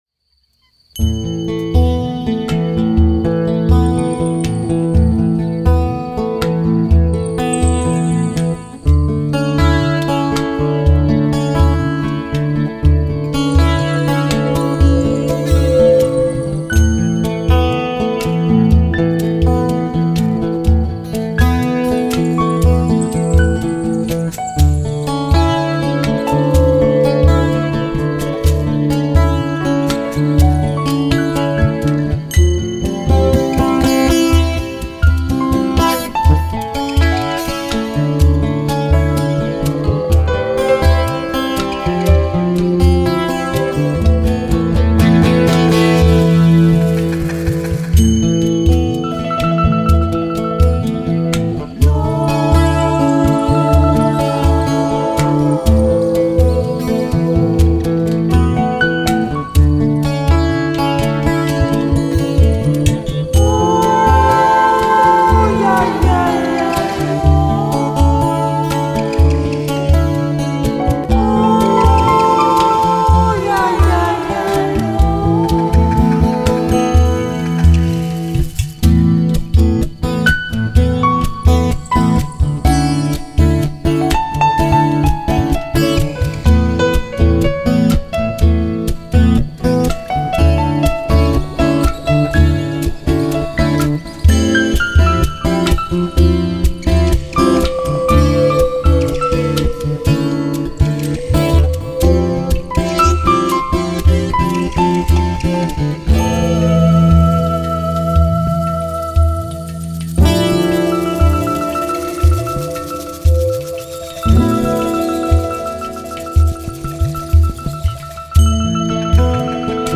Genre : Conte musical